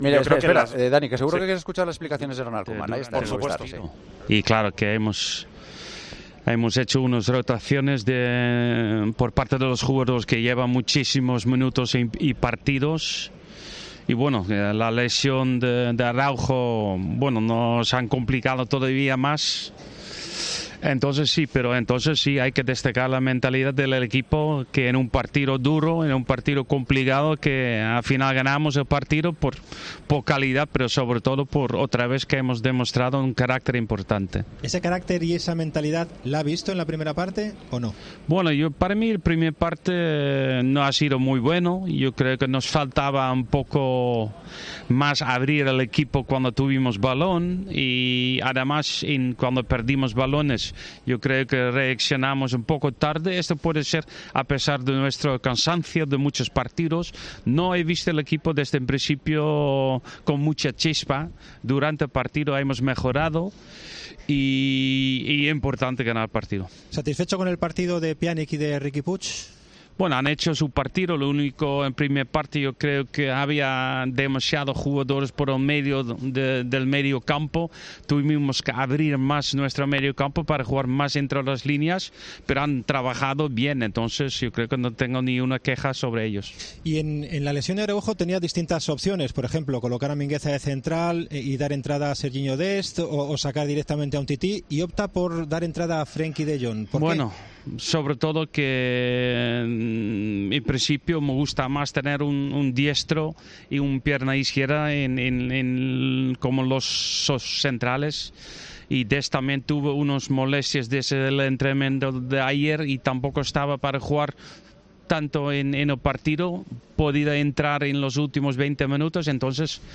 Escucha el análisis de Ronald Koeman tras el Betis - Barcelona, en Movistar: "En la segunda parte mejoramos. Estoy muy contento por Trincao, le hacía falta un gol como este".